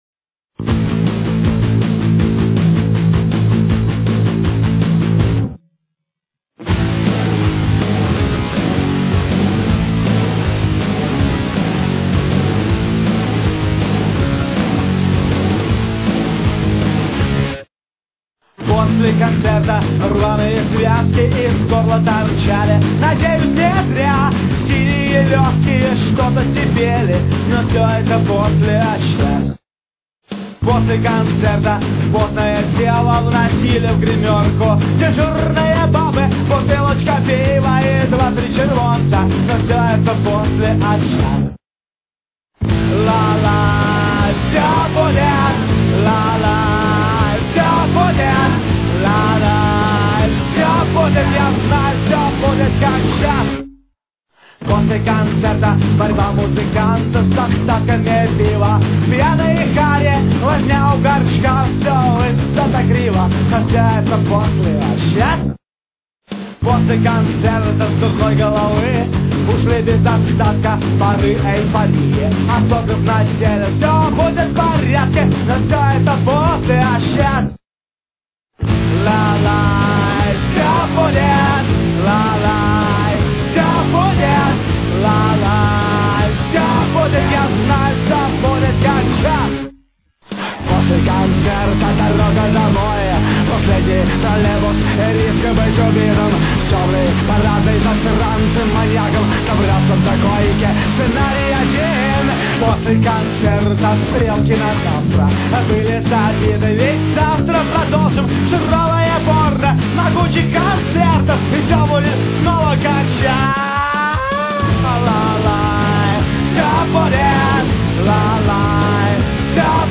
ПАНК ВЫСЩЕЙ КАТЕГОРИИ!!!